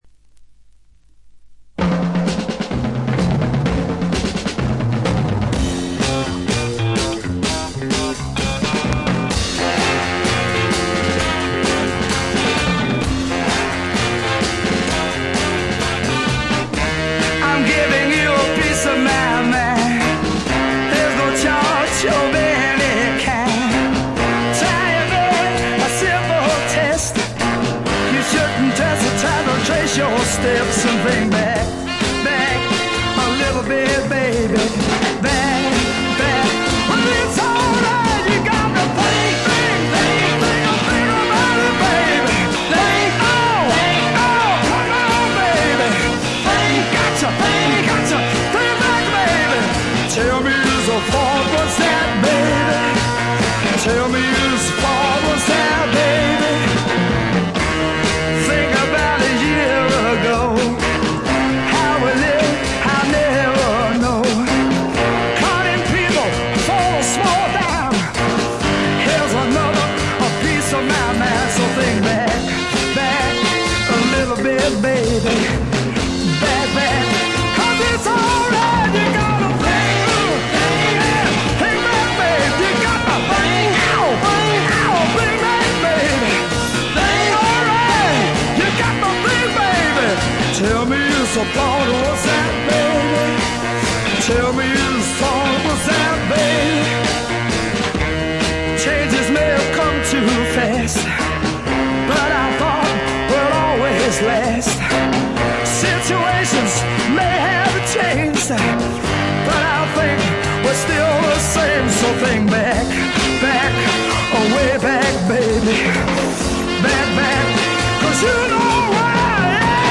ところどころでチリプチ、バックグラウンドノイズ。特に気になるような大きなノイズはありません。
試聴曲は現品からの取り込み音源です。